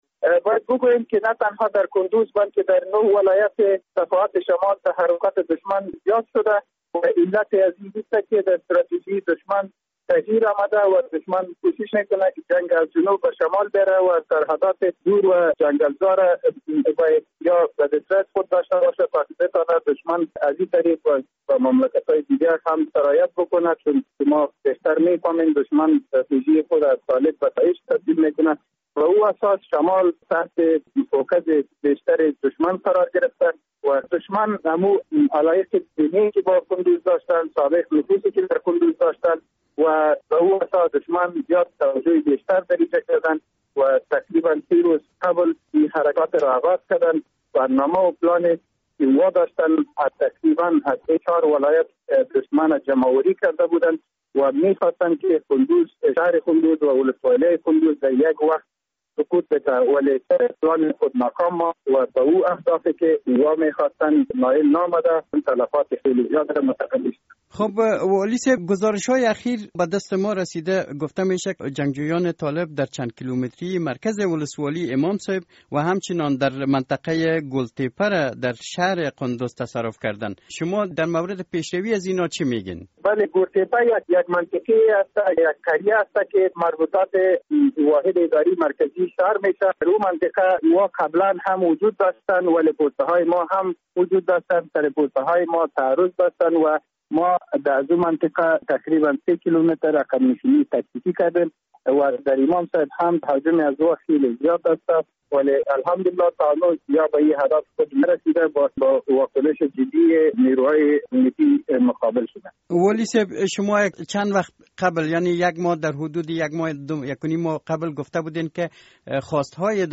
مصاحبه - صدا
عمر صافی والی قندز